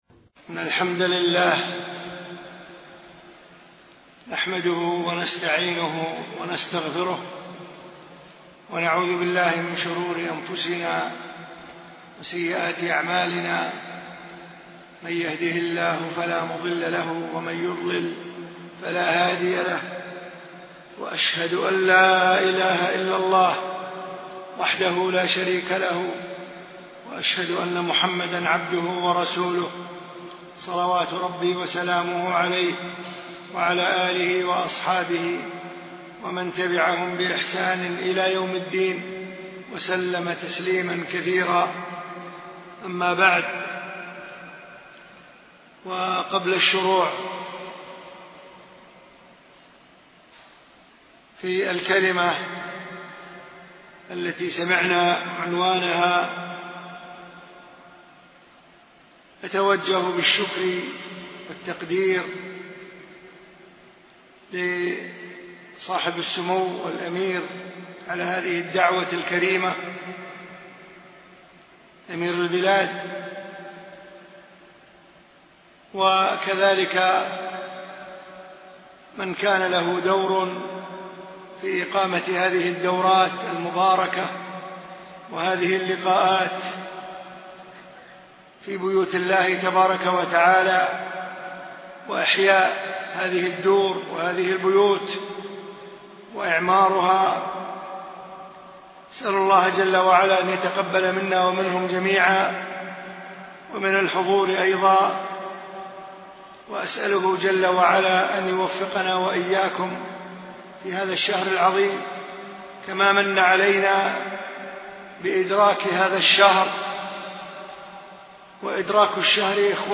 ألقيت المحاضرة في دولة الإمارات